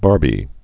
(bärbē)